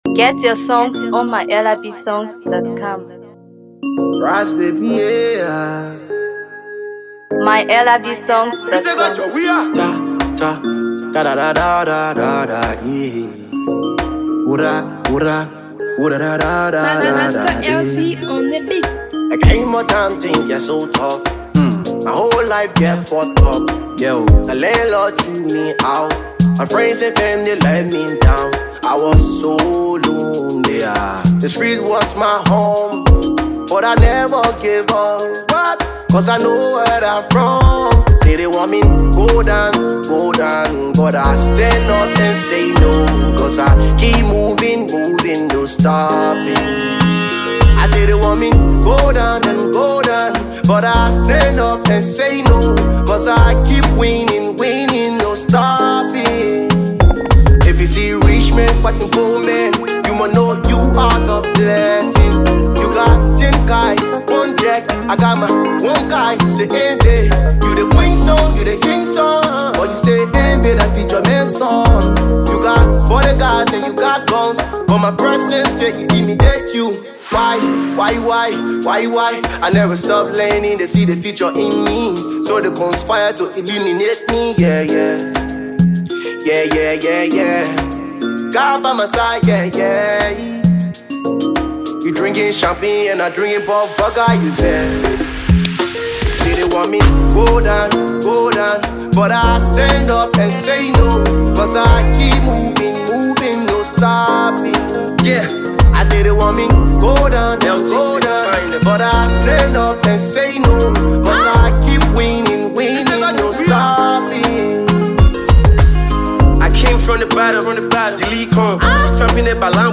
Afro PopHipcoMusic